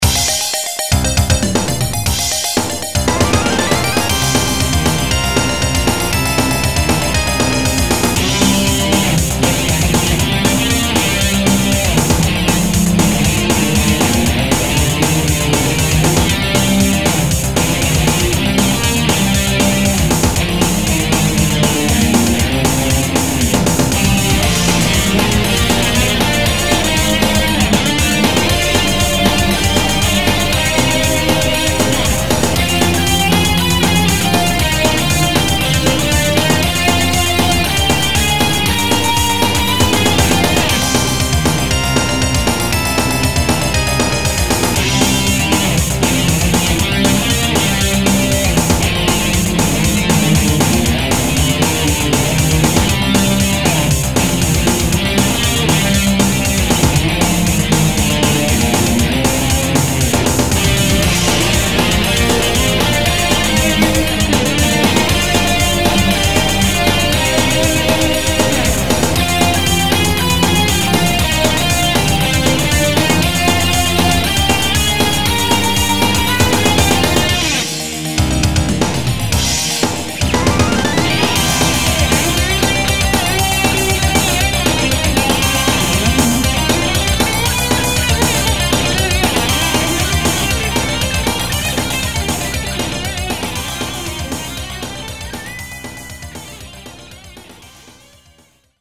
ゲーム音楽アレンジ集
録音状態が非常に悪い。